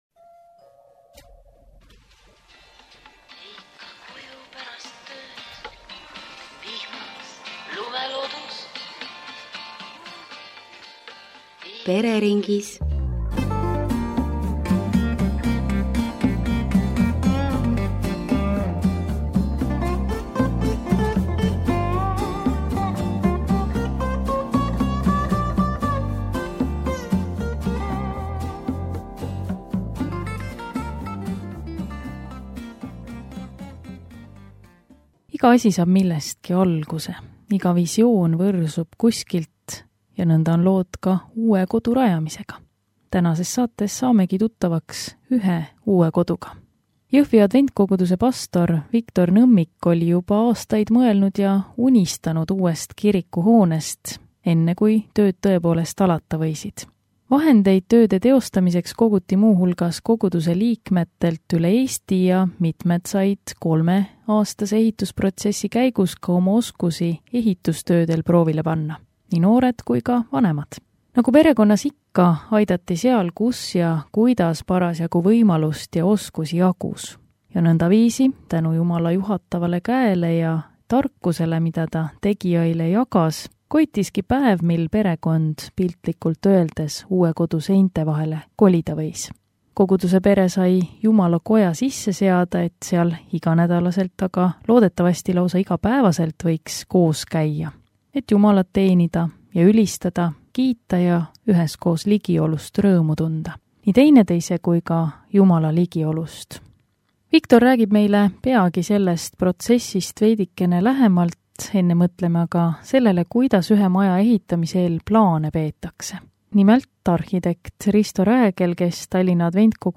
Saatesarja "Pereringis" saade Pereraadios. Saates räägitakse Jõhvi adventkiriku ehitusest, mis kestis 3 aastat.